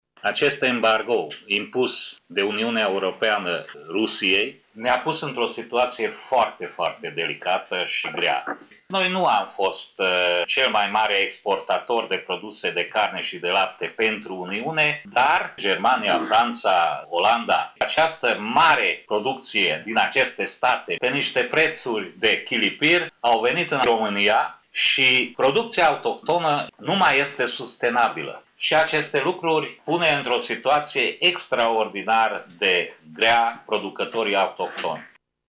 Ca soluție, s-a discutat reducerea TVA la cele două produse, de la 24 % la 9 la sută, a explicat deputatul UDMR: